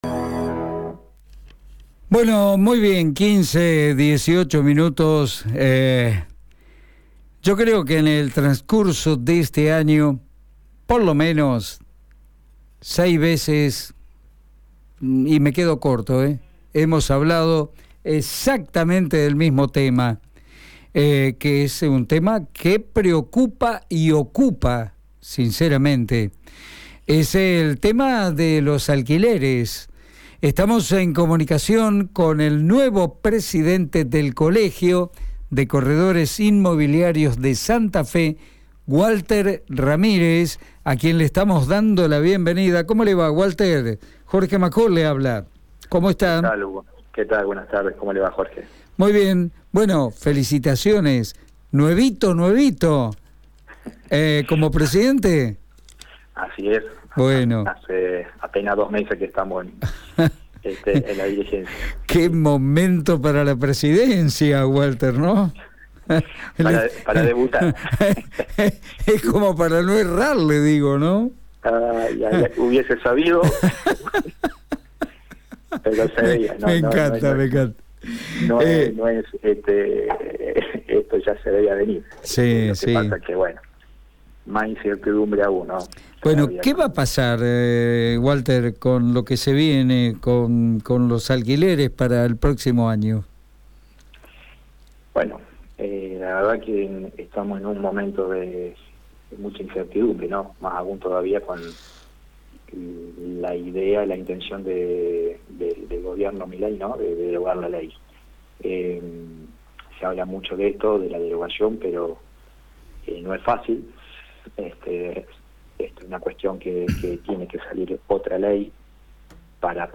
Hoy los propietarios están requisentes a firmar un nuevo contrato que se enmarque en la ley que se sancionó en octubre y que otra vez trata de 3 años, con ajuste semestral y un índice muy bajo», detalló el entrevistado.